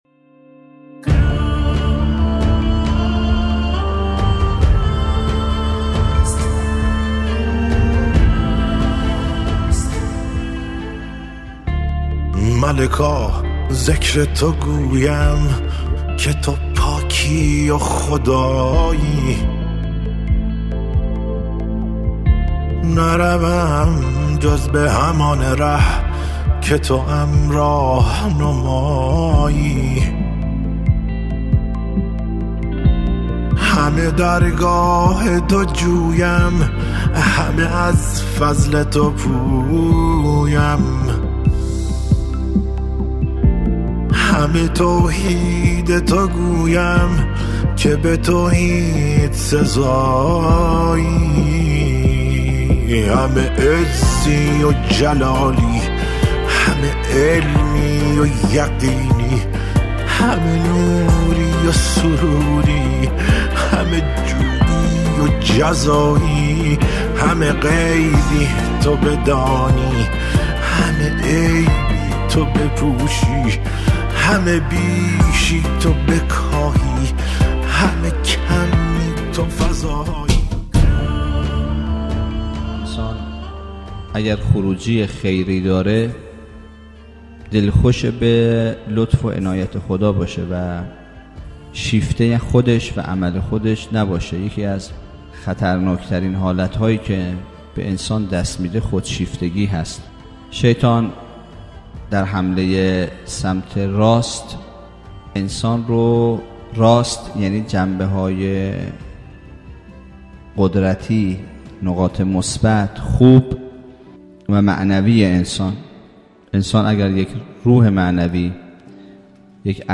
سخنرانی استاد محمد شجاعی